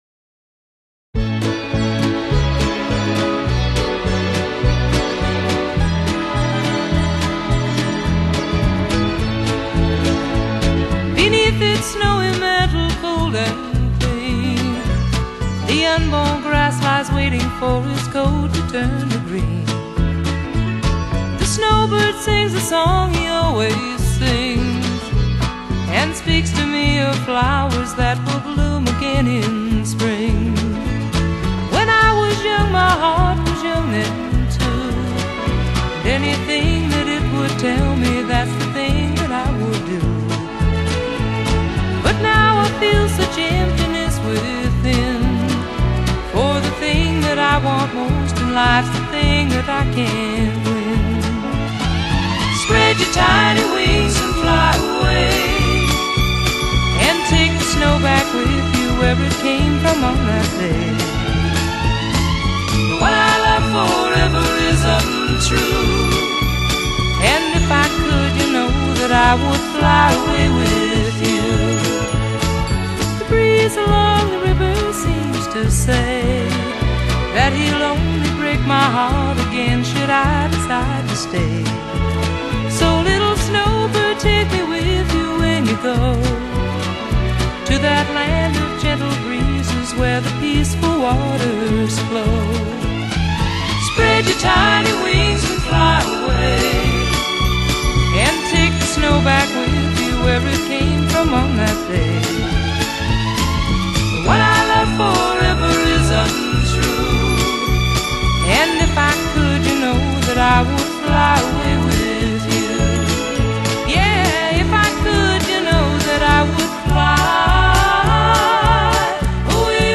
加拿大鄉村民謠天后